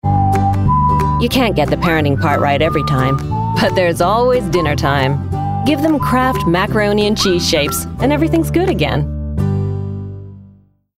40/50's North American, Versatile/Reassuring/Natural